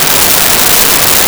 Static Loop 01
Static Loop 01.wav